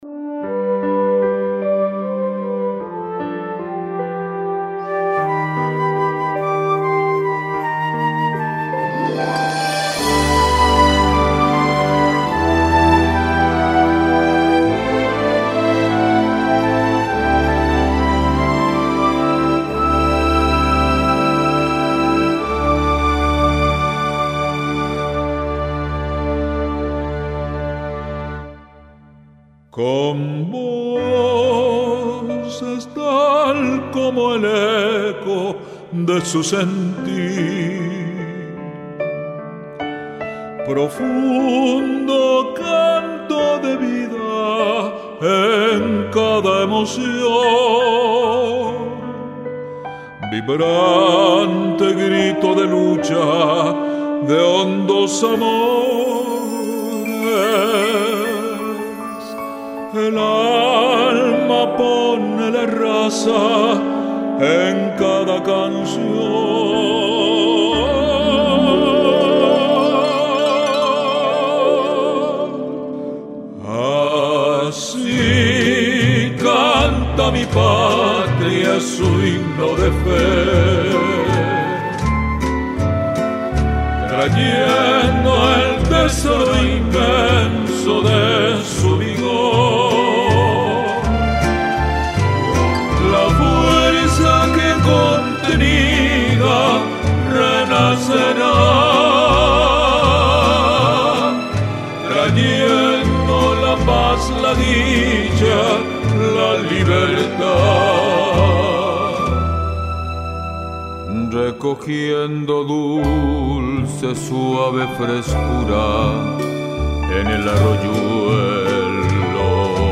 guarania